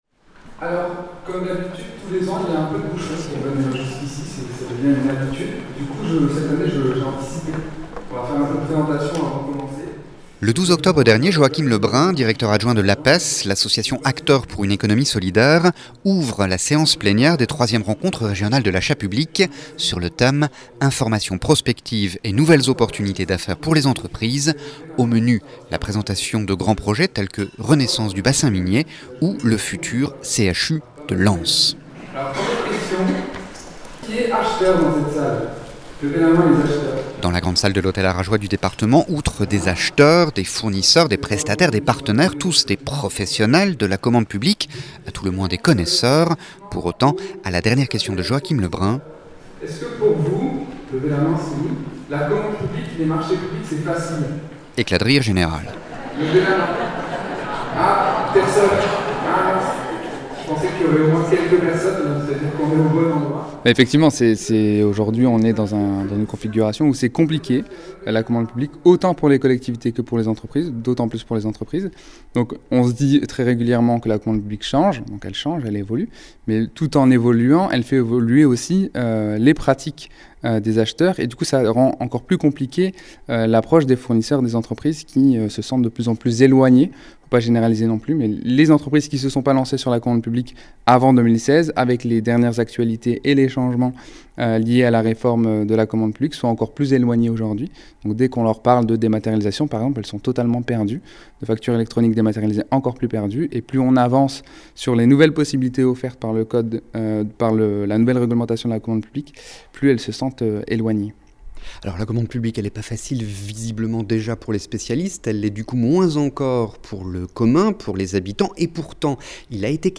L’achat public en question REPORTAGES/ENTRETIENS
Les 3èmes rencontres régionales de l’achat public organisées par l’ APES se tenaient à Arras le 12 octobre dernier. Au menu : débats autour de la commande publique, un sujet certes technique mais un véritable défi de l’économie sociale et solidaire pour des achats créateurs de richesses sociales, environnementales et économiques sur le territoire.